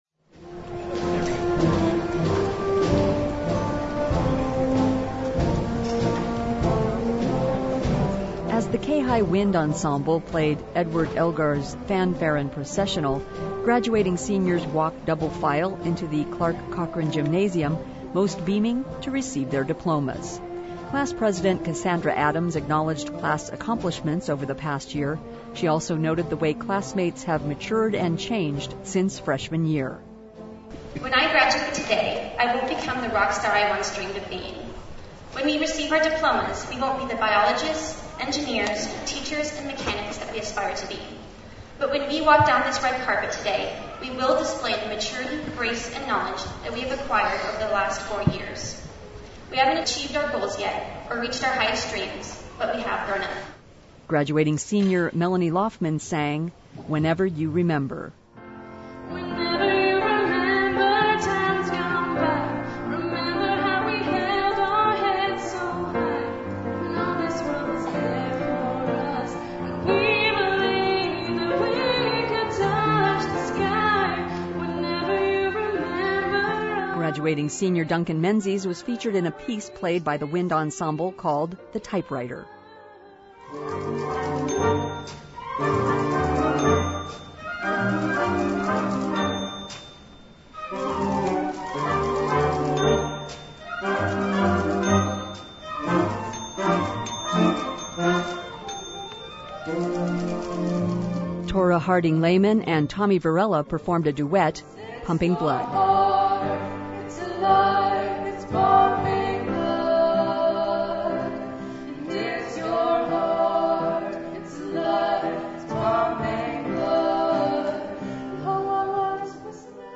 Smiles and cheers graced the Kayhi Gym as more than 130 students were honored Sunday during Ketchikan High School’s 2015 commencement ceremony.
The name of each graduate was read to applause and cheers.